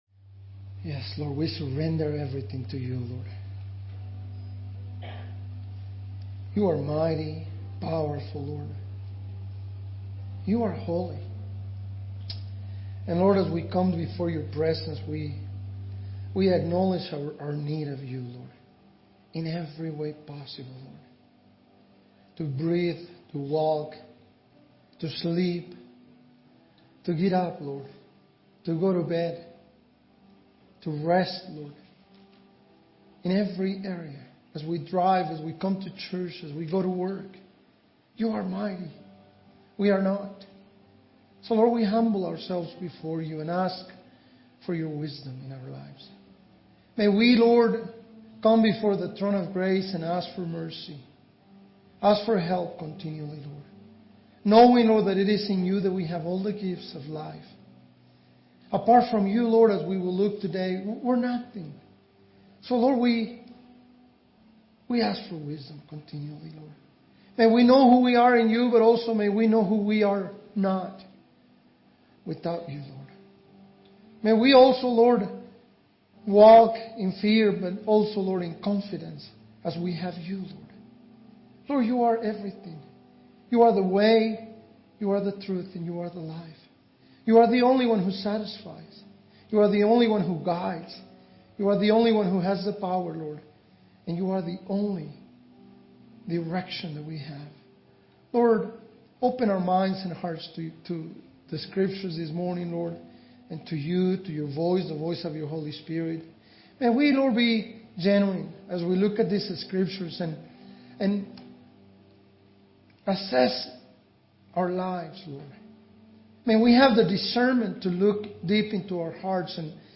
WPCC Sermons 2021